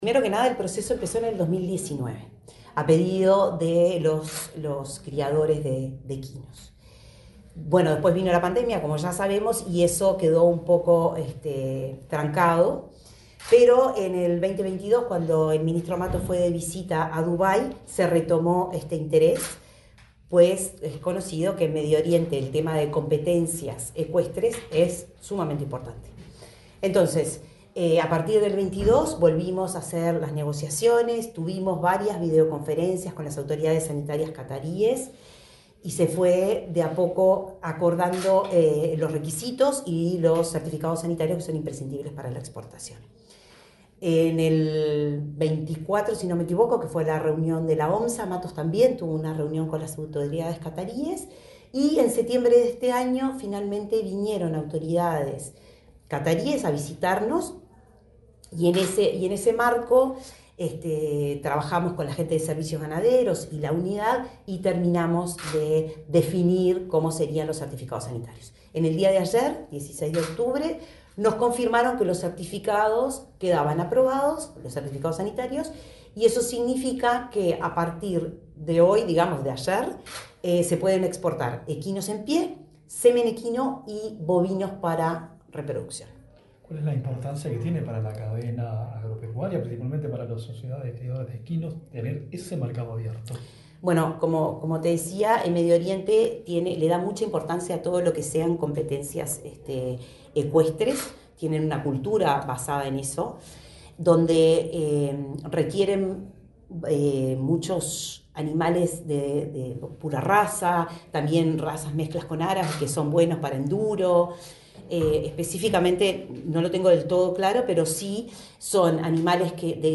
Entrevista a la directora de Asuntos Internacionales del MGAP, Adriana Lupinacci
La directora de Asuntos Internacionales del Ministerio de Ganadería, Agricultura y Pesca (MGAP), Adriana Lupinacci, dialogó con Comunicación